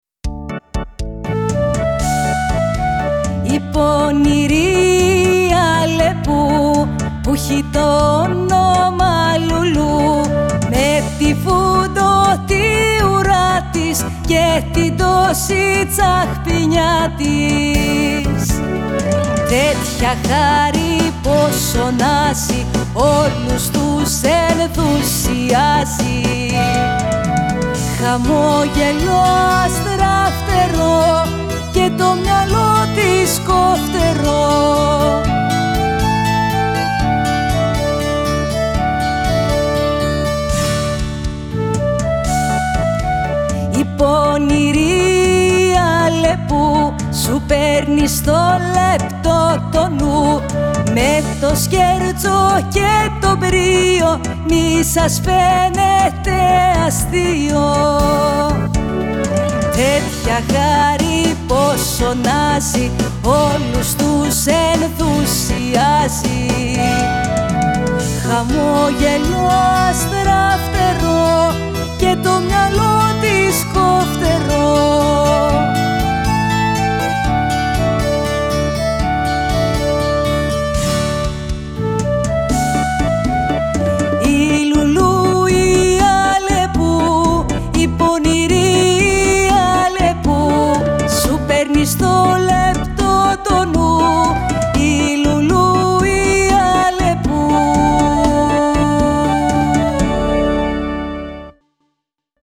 στο studio FREQ